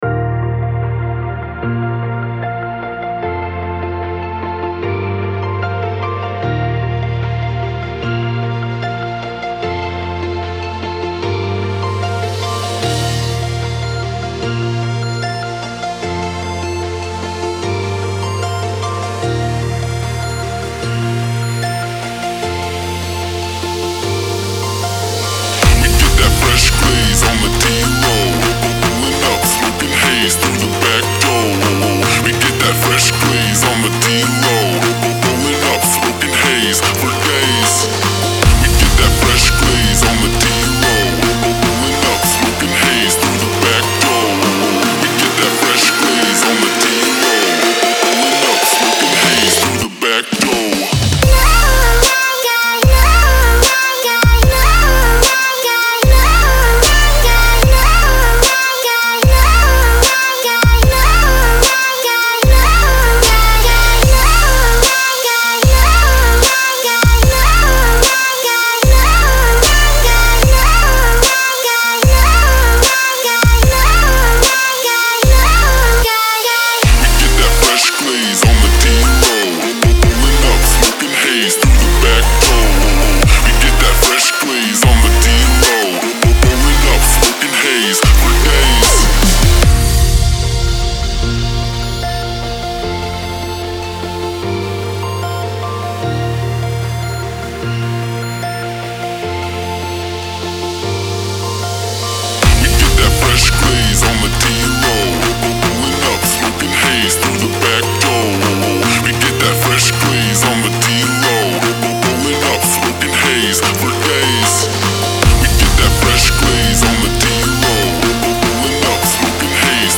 House, Energetic, Happy, Hopeful, Euphoric